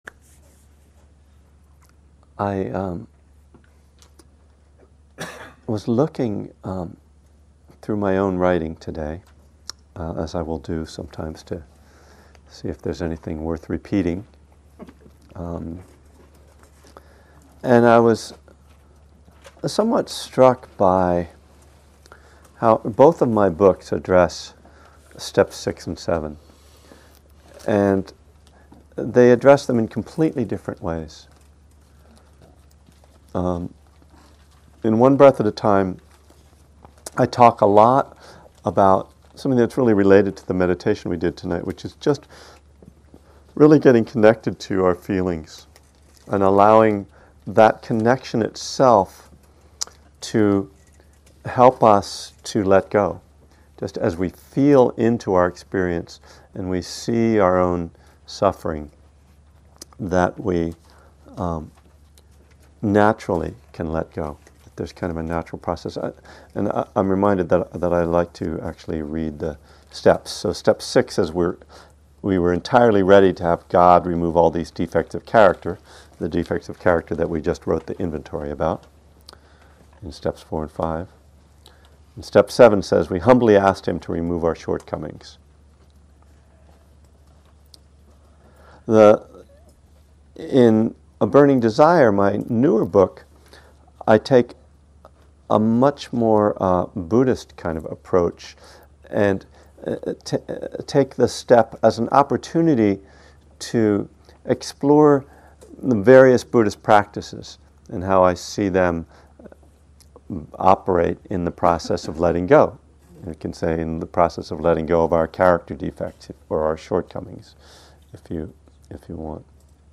From the Buddhism and the Twelve Steps class series at Spirit Rock in March 2011.